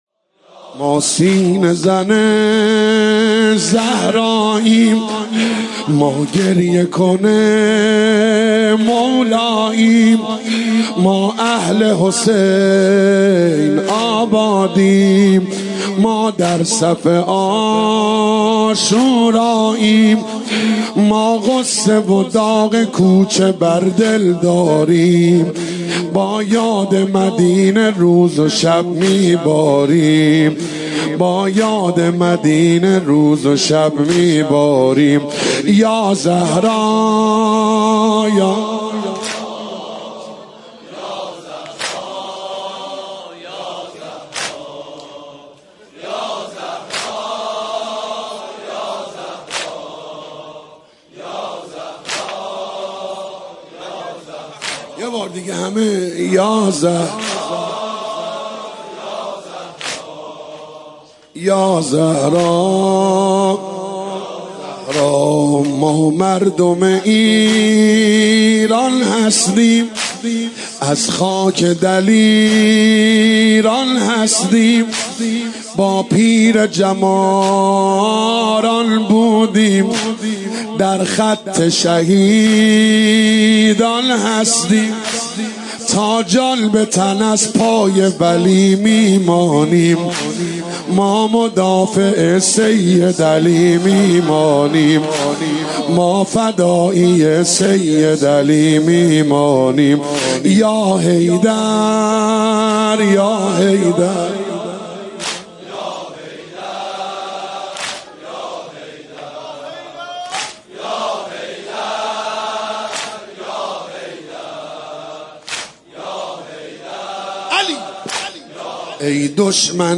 نوحه جديد
مداحی محرم